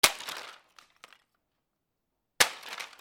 衝撃
/ H｜バトル・武器・破壊 / H-35 ｜打撃・衝撃・破壊　強_ナチュラル寄り
タッチノイズ最後にあり 『チャ』